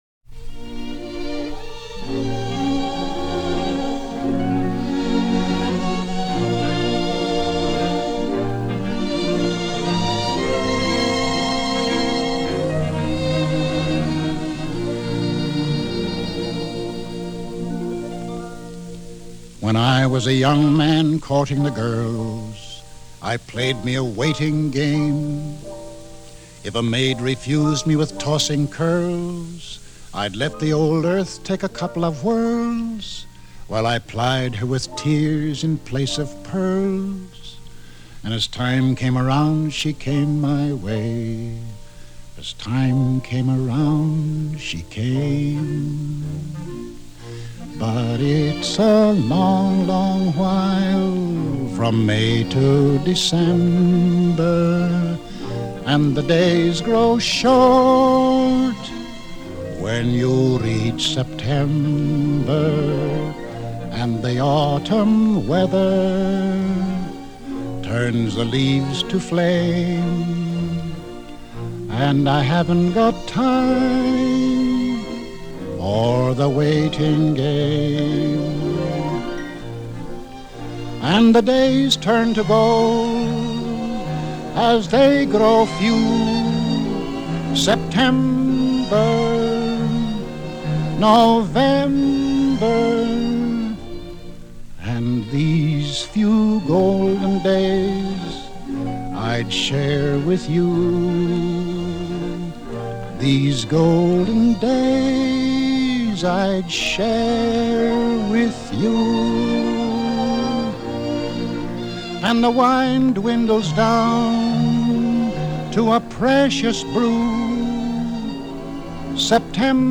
it was specially fashioned to match his limited vocal range.